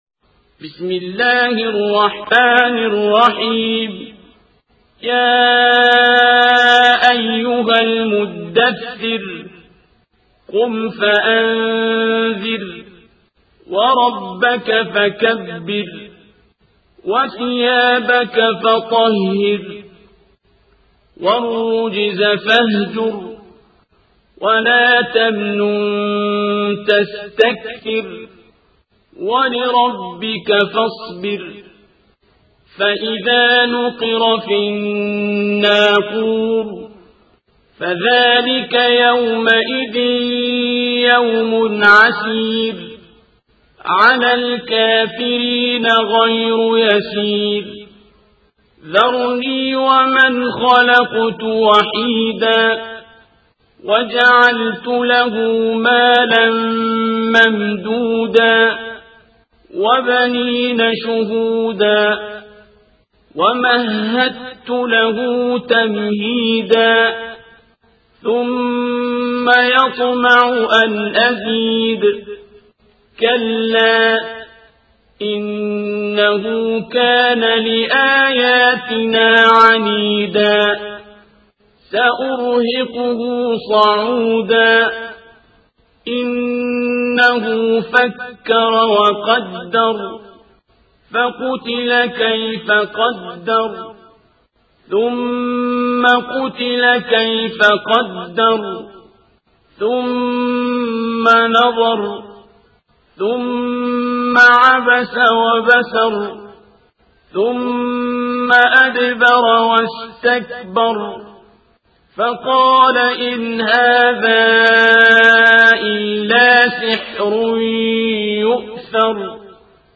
القارئ: الشيخ عبدالباسط عبدالصمد
تفاصيل : القرآن الكريم - سورة المدثر- الشيخ عبدالباسط عبدالصمد